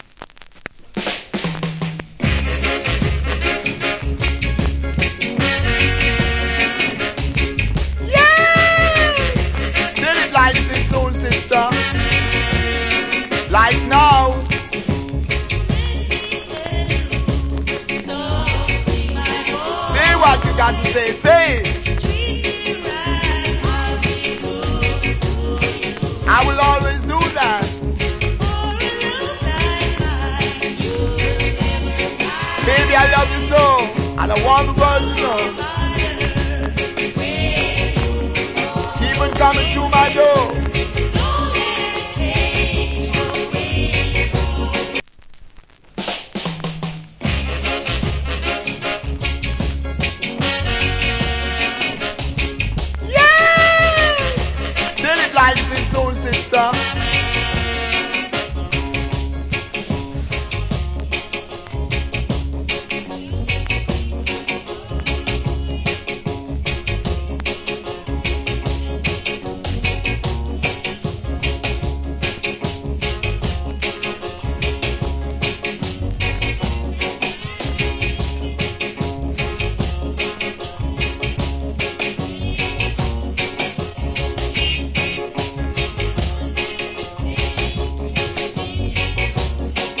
Wicked DJ.